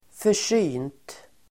Uttal: [för_s'y:nt]